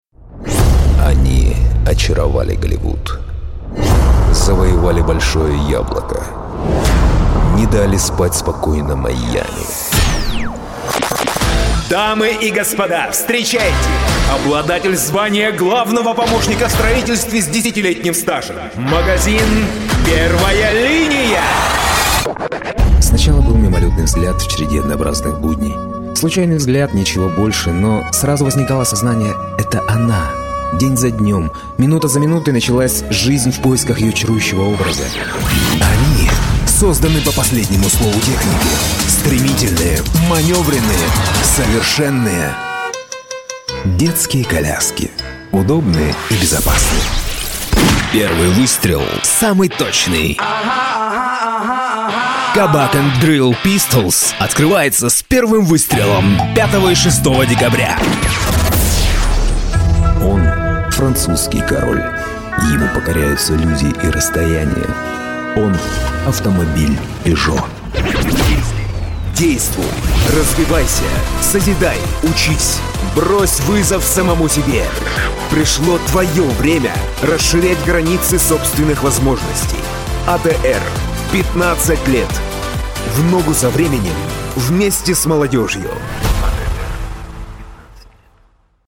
Muestras de voz nativa
Demo comercial
Mediana edad
Senior